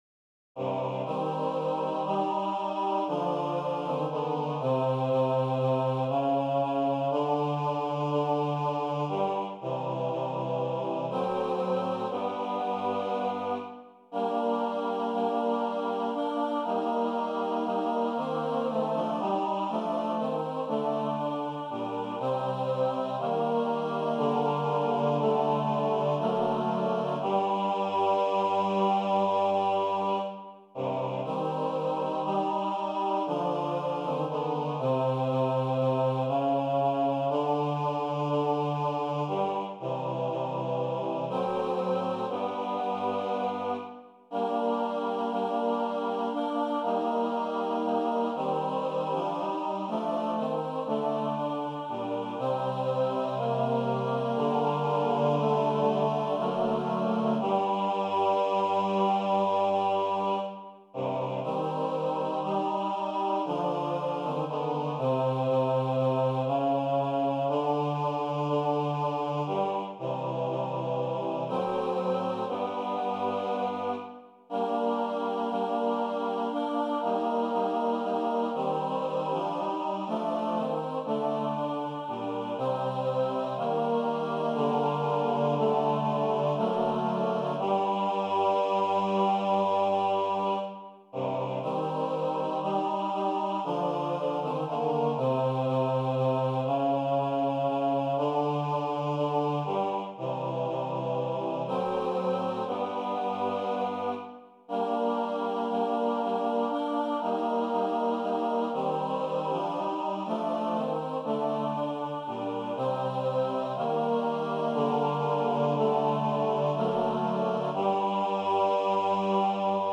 Title: Lammwirts Klagelied Composer: Hugo Distler Lyricist: Eduard Mörike Number of voices: 3vv Voicing: TTB Genre: Secular, Partsong
Language: German Instruments: A cappella